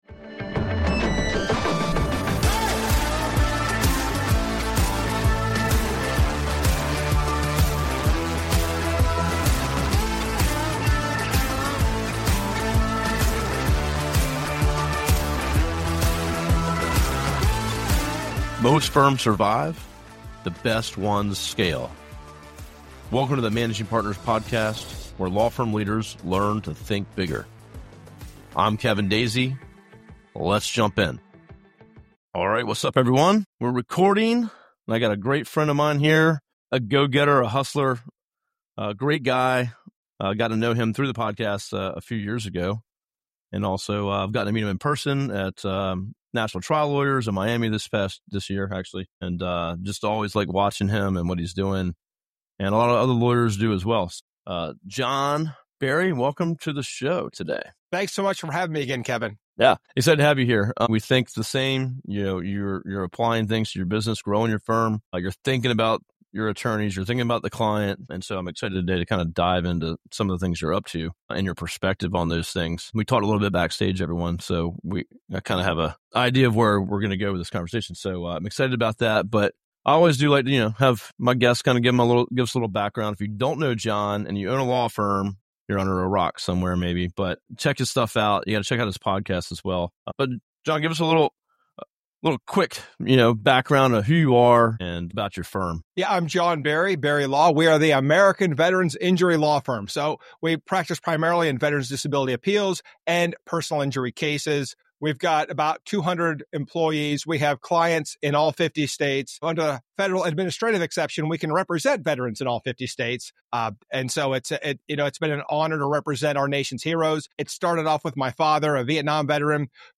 This candid conversation covers culture, leadership, branding, and the future of AI in law firms.